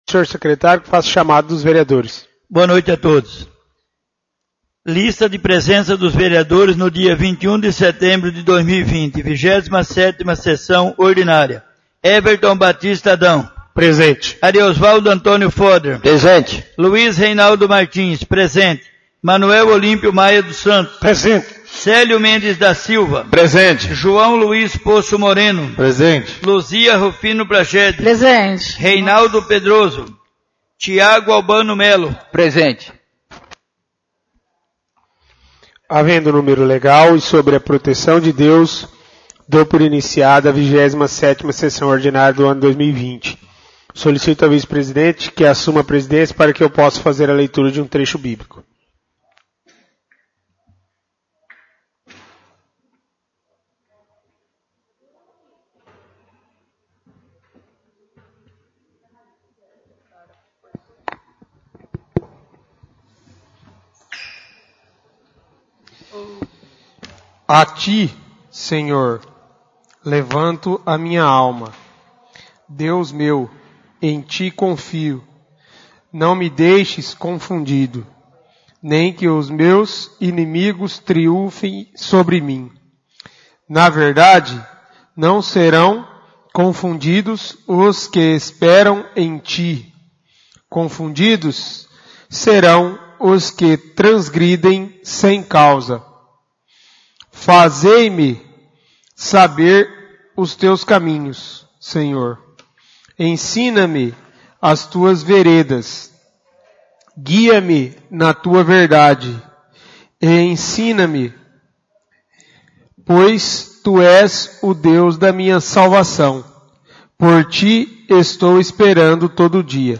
27° SESSÃO ORDINÁRIA — CÂMARA MUNICIPAL